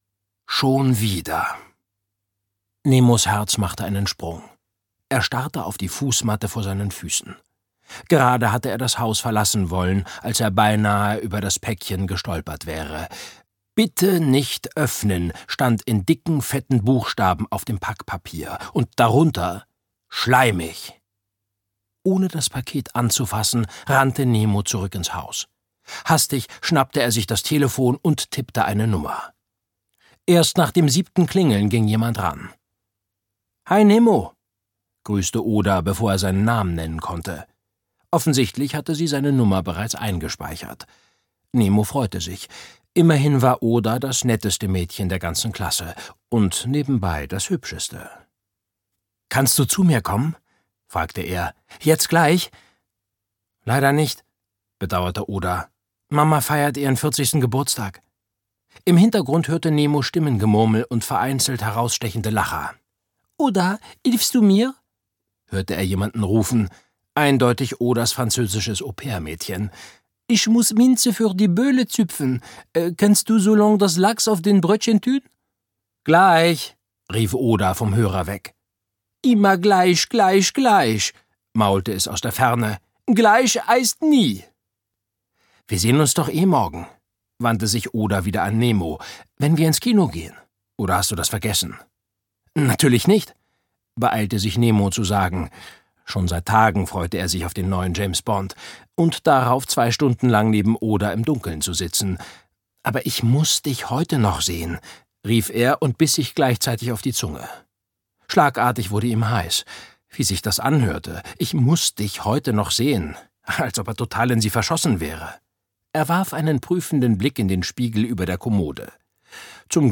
Bitte nicht öffnen 2: Schleimig! - Charlotte Habersack - Hörbuch